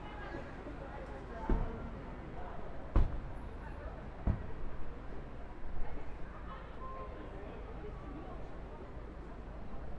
Environmental
UrbanSounds
Streetsounds